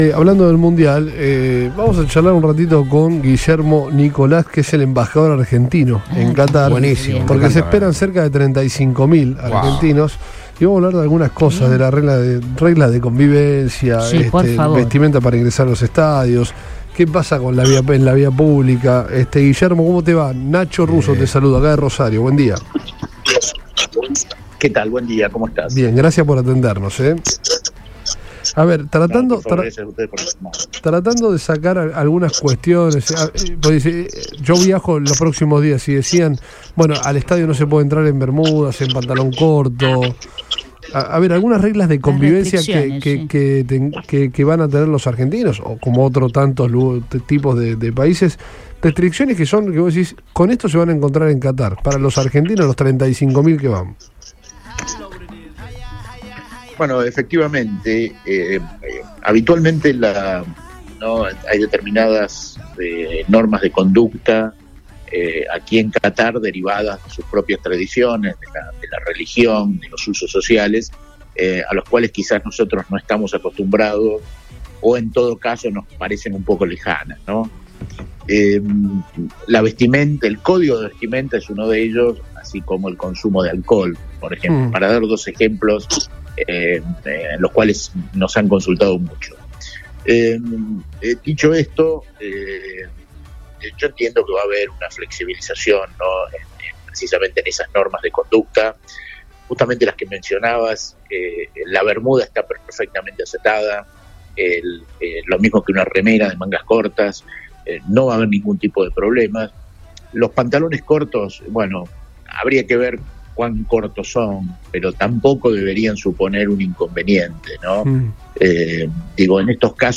en Todo Pasa por Radio Boing antes del comienzo del Mundial de Fútbol que se disputará en ese país.